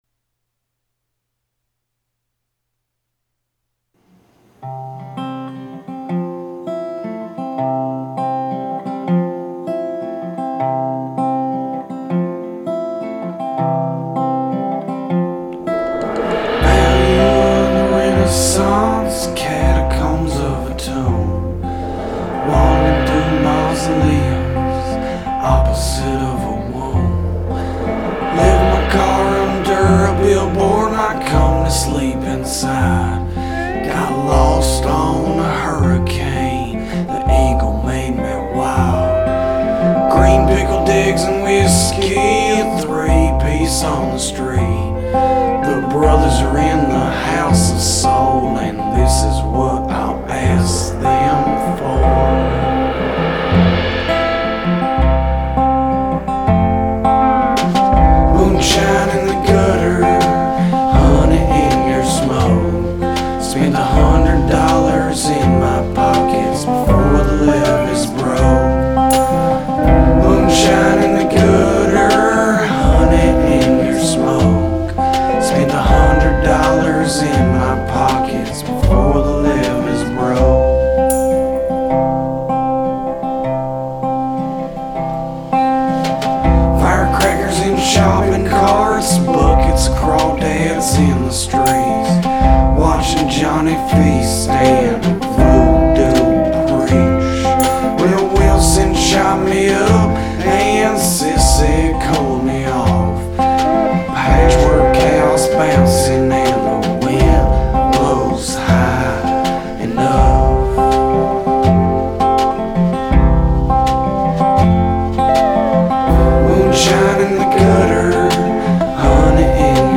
outfit is releasing its first full-band album.
modern psychedelic Americana